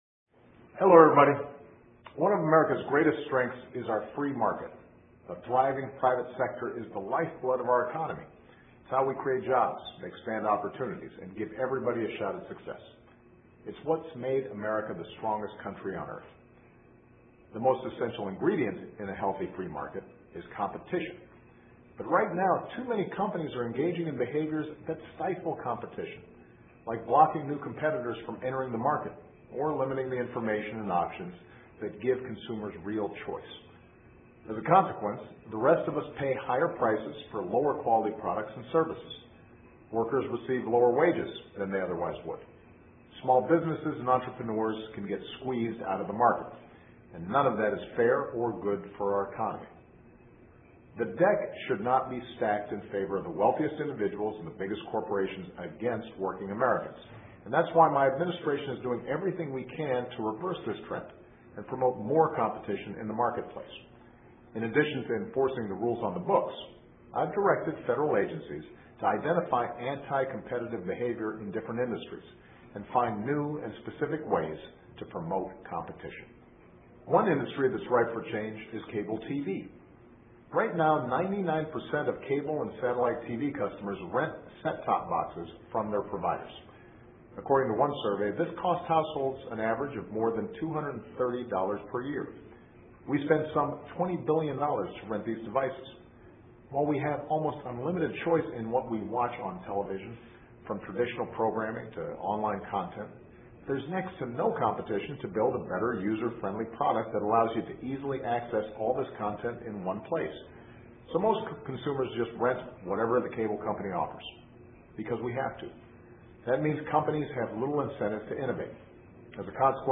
奥巴马每周电视讲话：总统希望所有人都受益于自由市场 听力文件下载—在线英语听力室